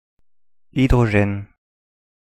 Ääntäminen
IPA : /ˈhaɪ.dɹə.dʒən/